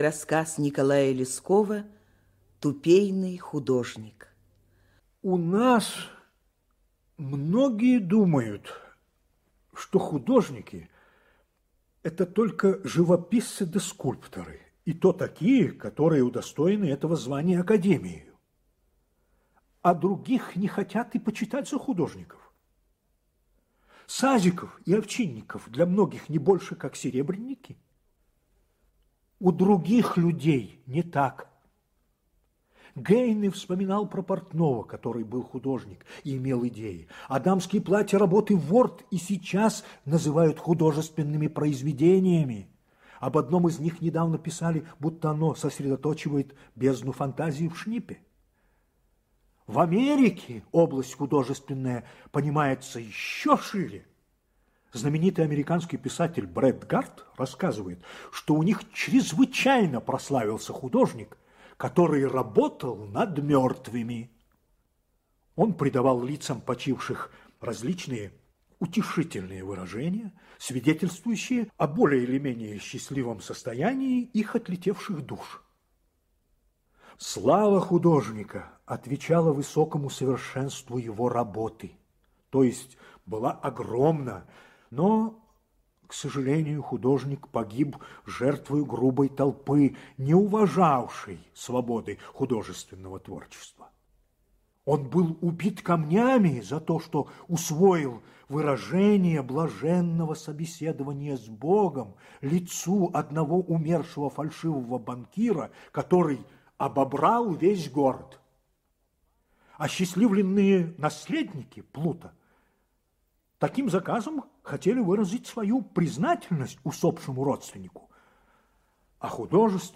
Радиопостановка Главной редакции радиовещания для детей Всесоюзного радио СССР по одноимённому рассказу русского писателя Николая Лескова, 1989 г.